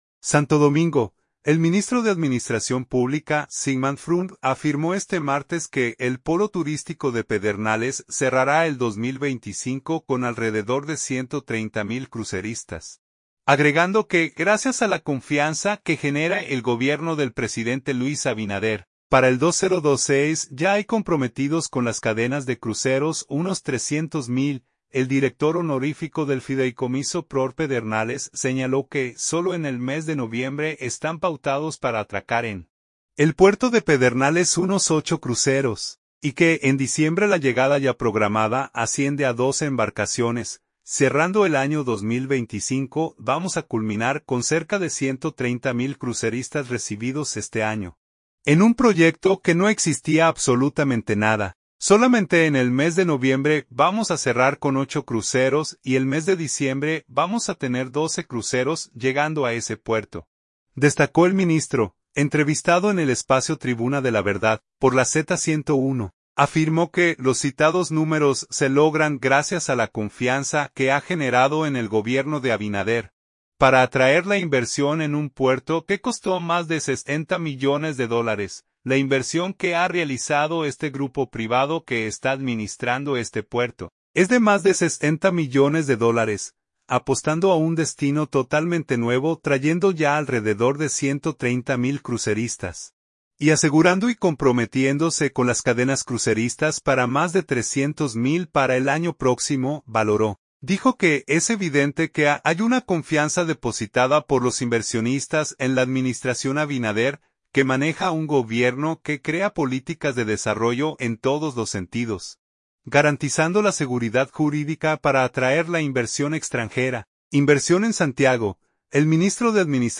Entrevistado en el espacio Tribuna de la Verdad, por la Z101, afirmó que los citados números se logran gracias a la confianza que ha generado en el gobierno de Abinader, para atraer la inversión en un puerto que costó más de 60 millones de dólares.